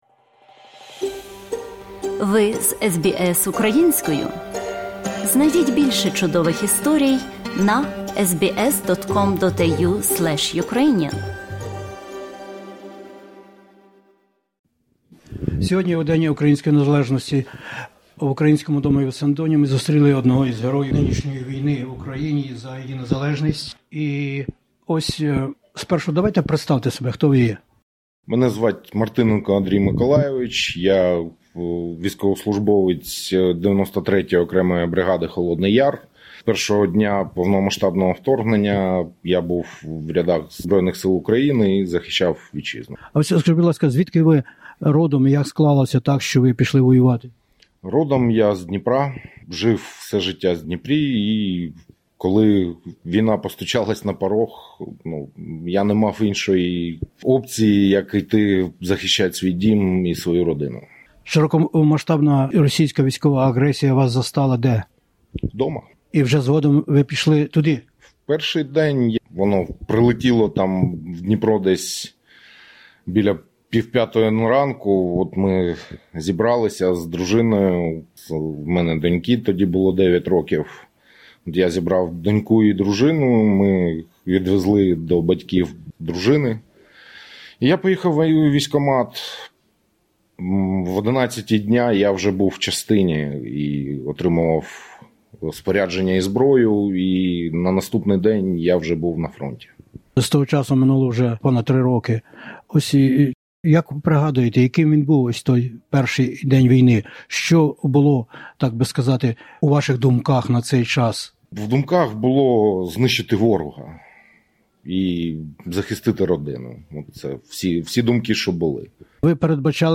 ветеран війни